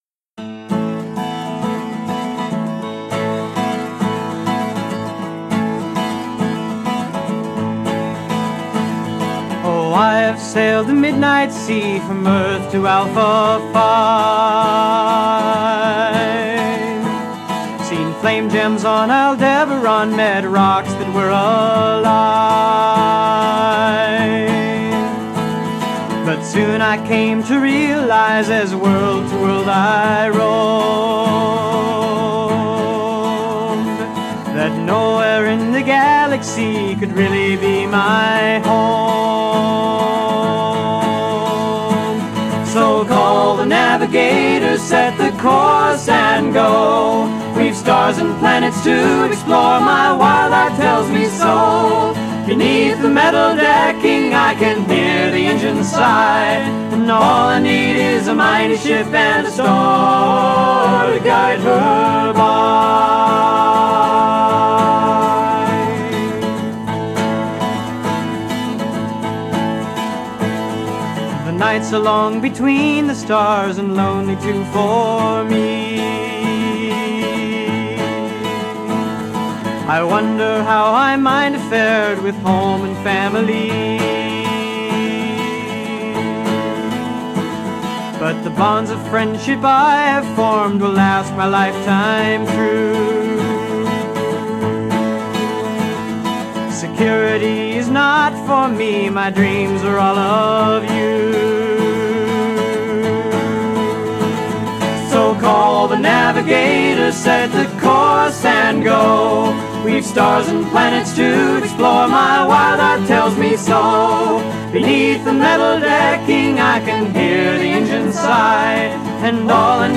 которая занималась публикацией музыки в жанре "филк"